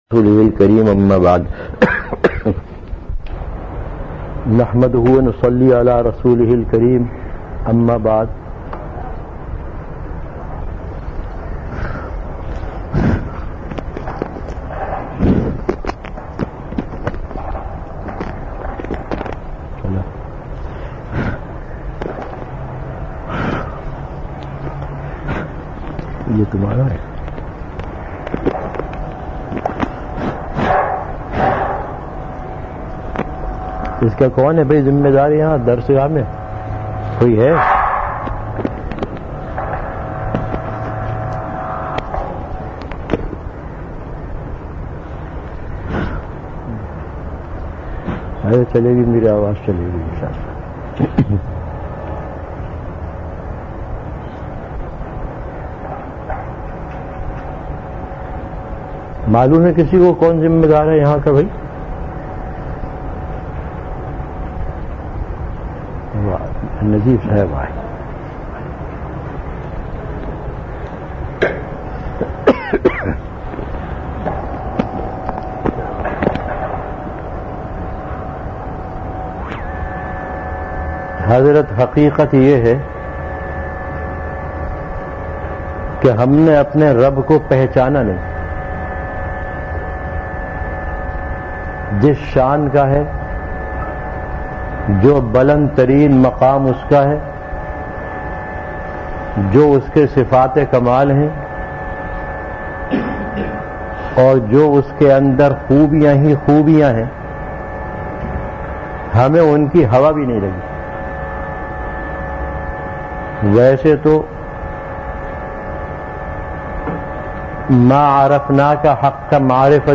Delivered at Jamia Masjid Bait-ul-Mukkaram, Karachi.
Bayanat · Jamia Masjid Bait-ul-Mukkaram, Karachi
Event / Time Before Juma Prayer